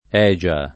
Egia [ $J a ]